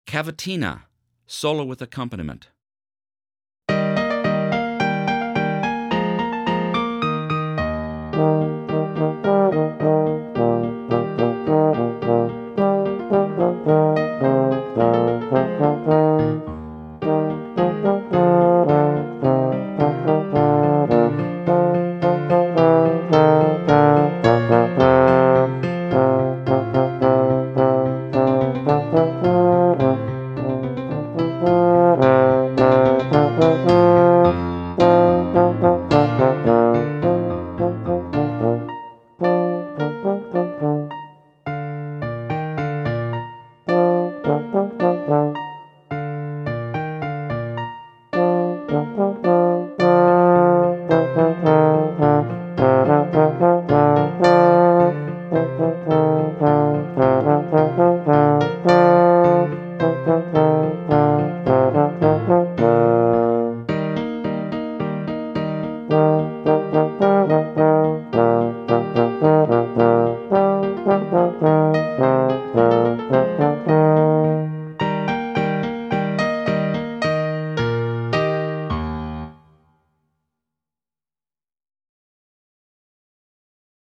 Solo with Piano Accompaniment – Performance Tempo
13-Cavatina-Solo-With-Accompanimen.mp3